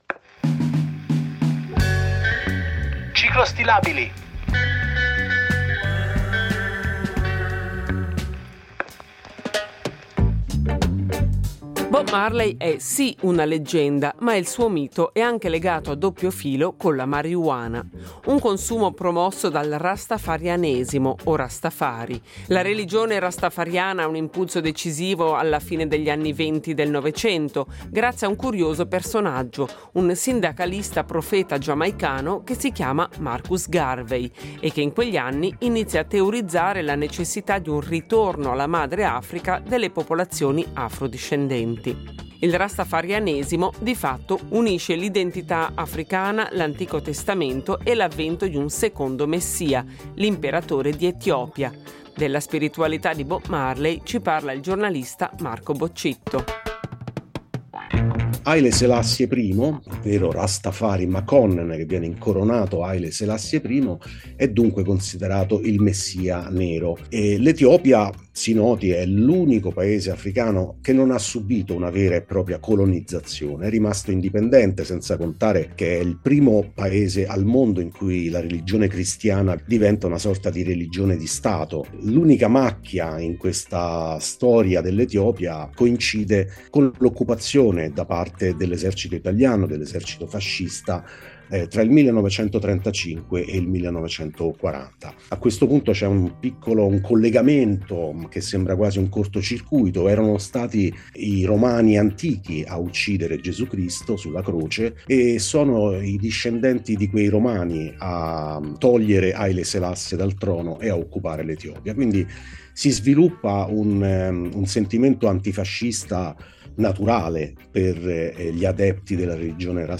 ha intervistato il giornalista e conduttore radiofonico